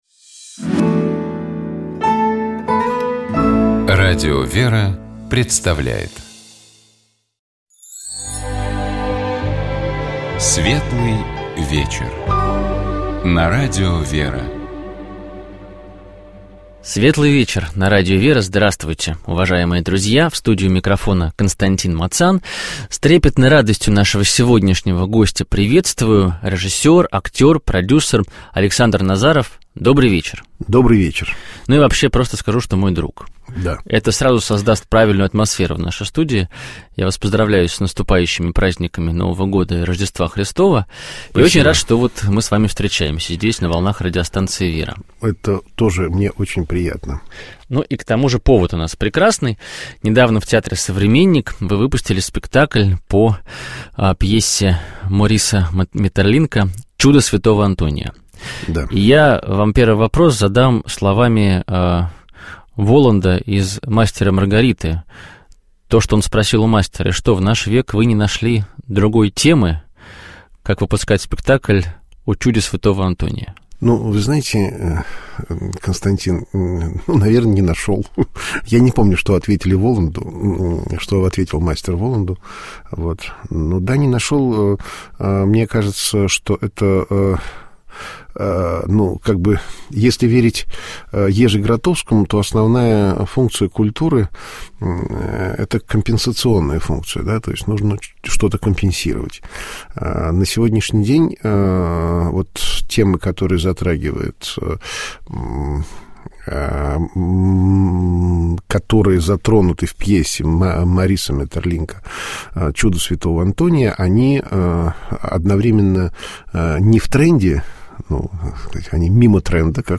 У нас в гостях был режиссер, актер, продюсер